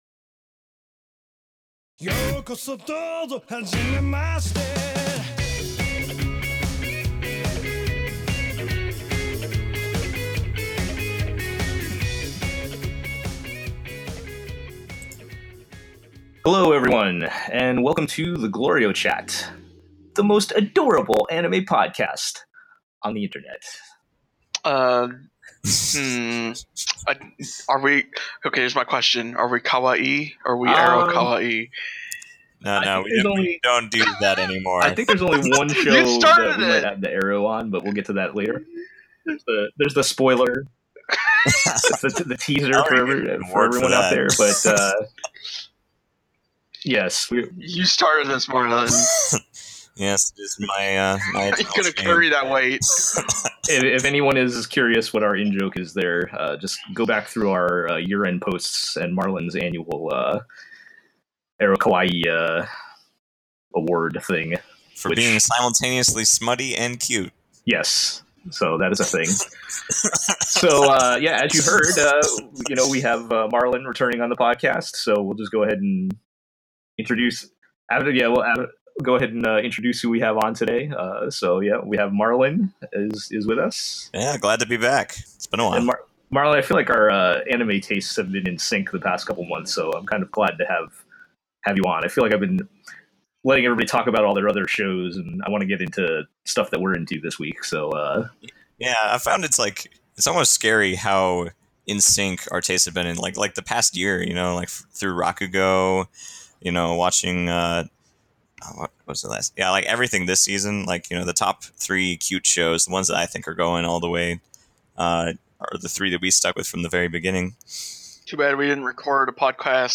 Seriously though, apologies for the poor sound quality, we will do better next time.